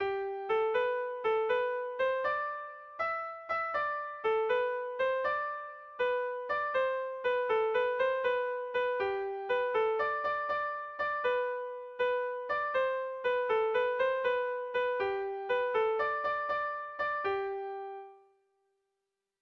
Air de bertsos - Voir fiche   Pour savoir plus sur cette section
Dantzakoa
ABD